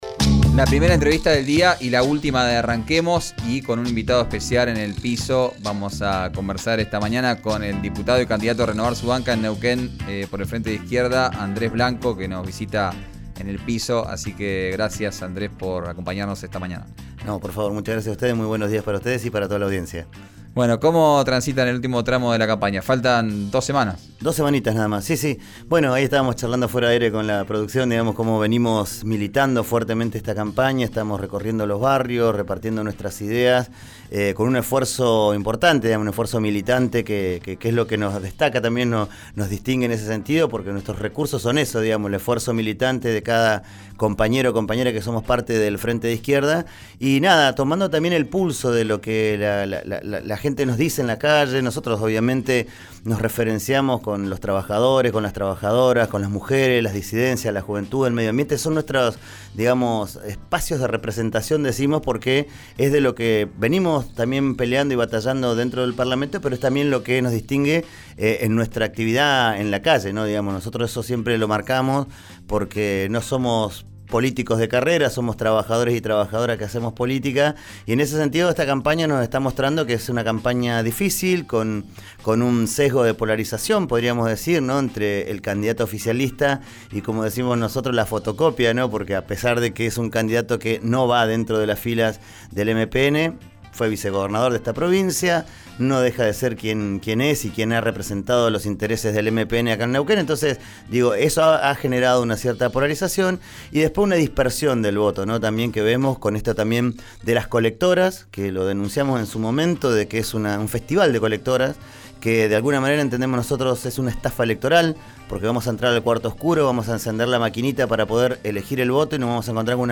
El diputado y candidato a renovar su banca en Neuquén por el Frente de Izquierda visitó el estudio de RÍO NEGRO RADIO. Escuchá la entrevista en 'Arranquemos'.